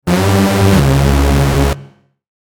Whammy Sound Effect
This whammy sound effect creates a quick, elastic pitch bend with a fun, cartoon-style feel. It adds humor, surprise, and energy to videos, games, animations, and transitions.
Whammy-sound-effect.mp3